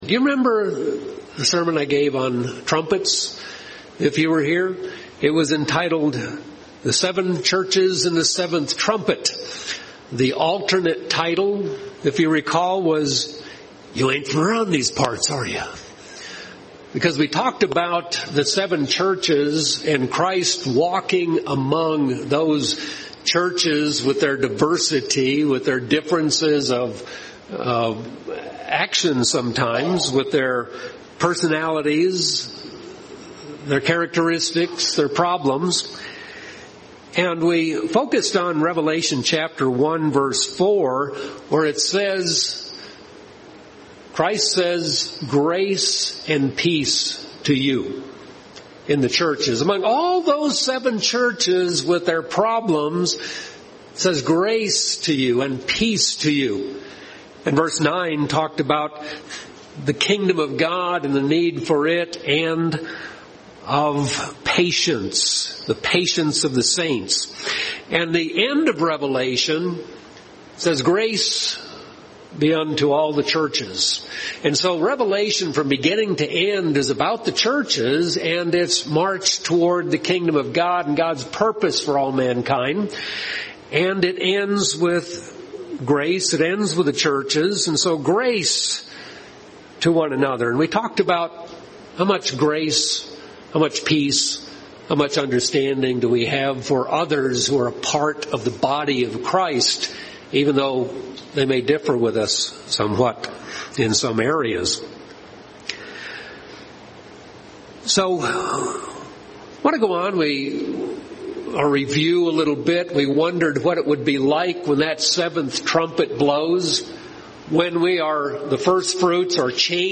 On Trumpets we discussed the relationship of the 7 Churches with the 7th Trumpet. This sermon is a follow up and will discuss the relationship with the 7 Churches to the Millennium and the Great White Throne Judgment.